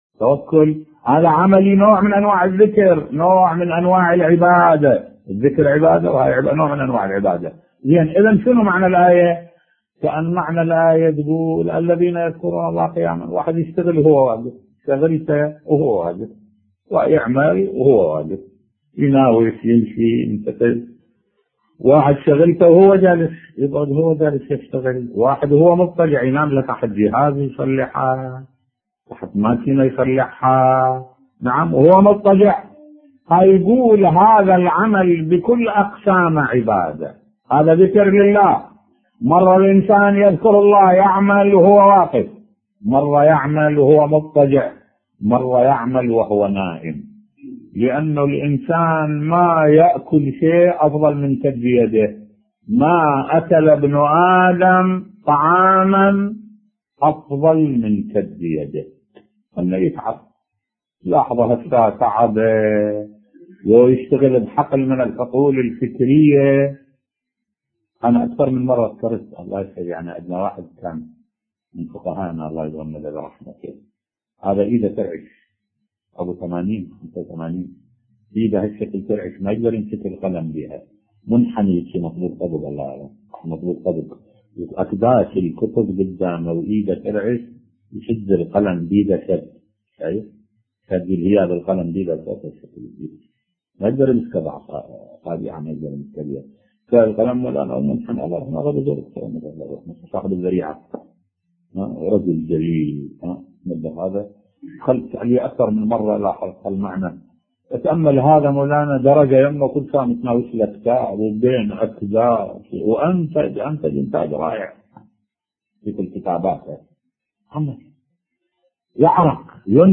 ملف صوتی اشادة بعطاء الأغا بزورك الطهراني صاحب الذريعة بصوت الشيخ الدكتور أحمد الوائلي